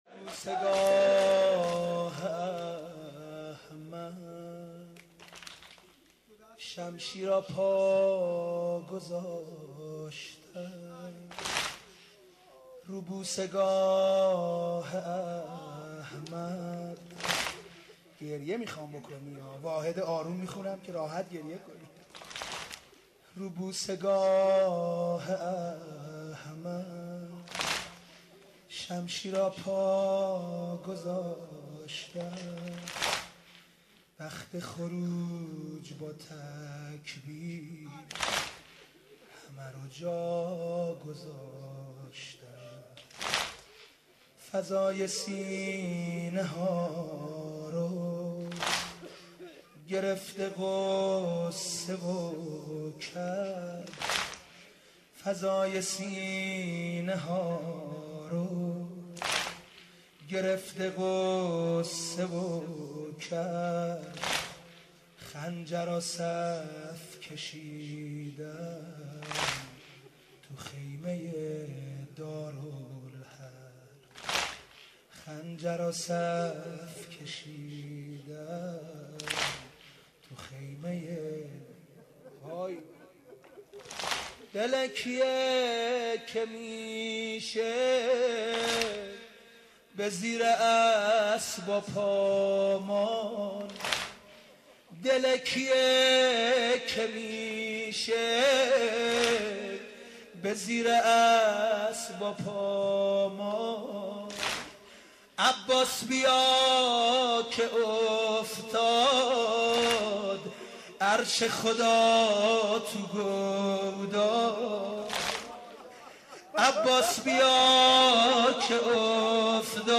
شور، زمزمه